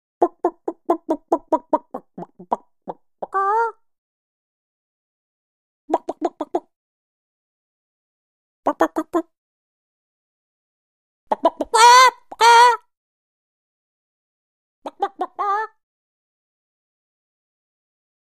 Chicken Clucks - 5 Effects; Chicken Clucks.